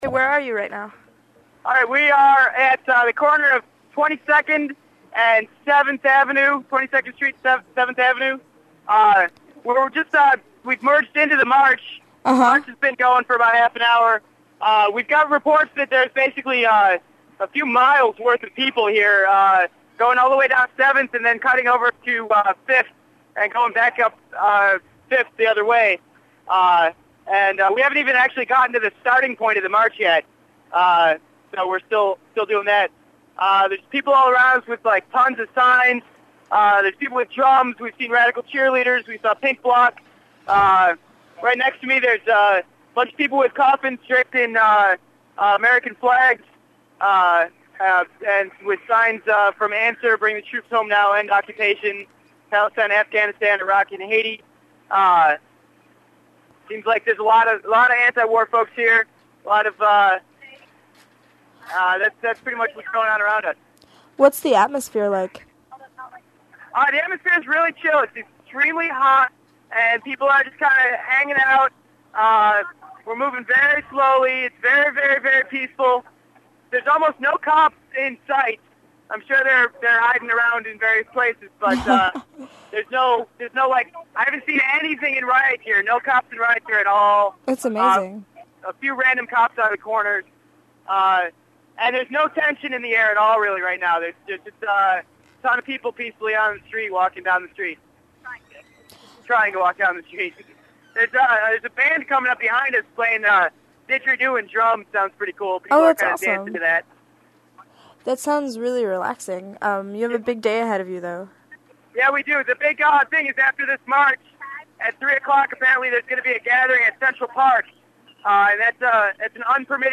Report back from march in NYC, 12:50pm Sunday